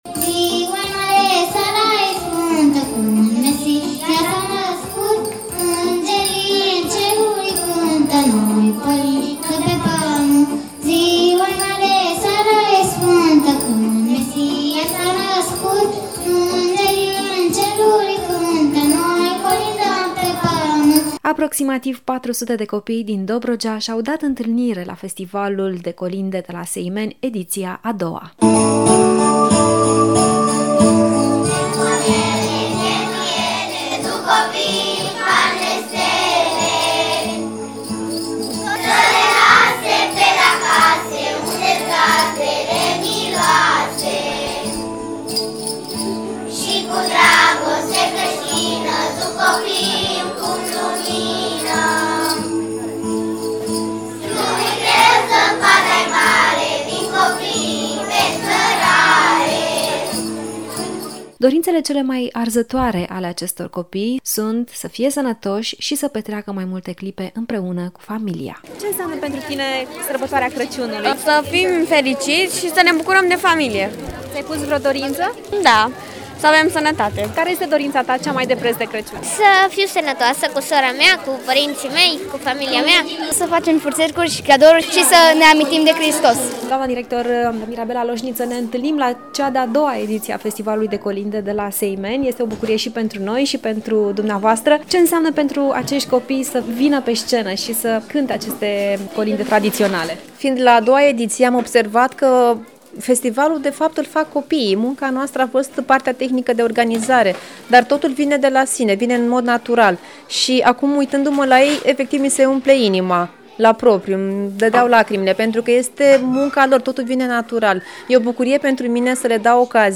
Festivalul de colinde Sărbătoarea Crăciunului, Tradiții și Obiceiuri din Dobrogea”, organizat și găzduit de Școala Generală Nr. 1 din Seimeni, a ajuns la a II-a ediție.
Aproximativ 400 de copii din Seimeni, Cernavodă, Saligny și Rasova s-au adunat să vestească Nașterea Domnului.